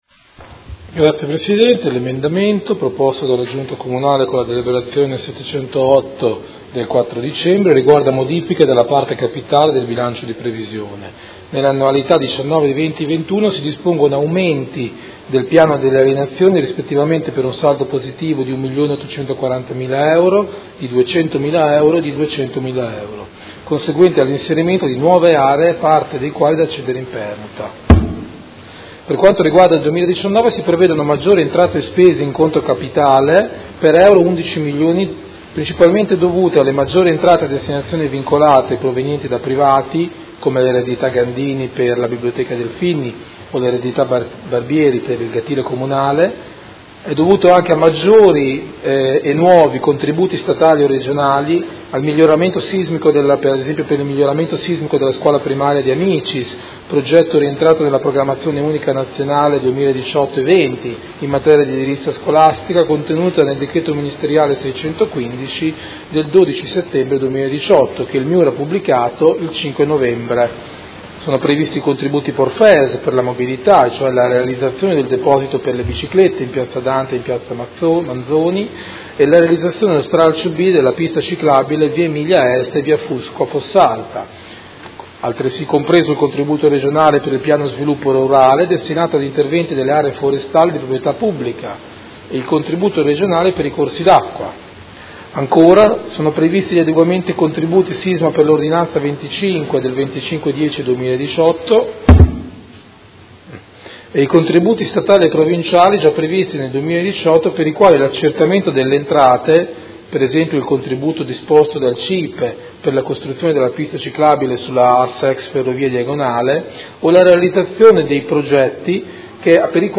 Andrea Bosi — Sito Audio Consiglio Comunale
Seduta del 20/12/2018. Presenta emendamento Prot. Gen. 197121